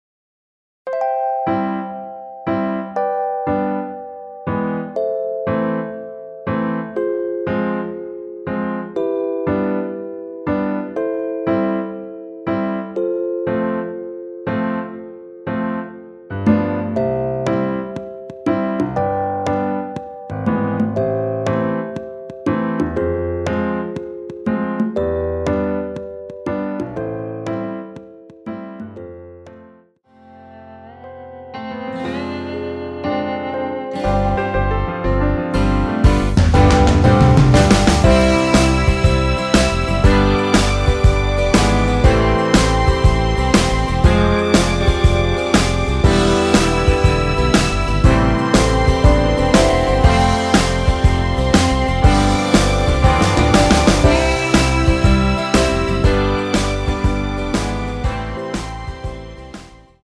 남성분이 부르실수 있는 키로 제작 하였습니다.(미리듣기 참조)
엔딩이 페이드 아웃이라 라이브 하시기 좋게 엔딩을 만들어 놓았습니다.
원키에서(-6)내린 MR입니다.
앞부분30초, 뒷부분30초씩 편집해서 올려 드리고 있습니다.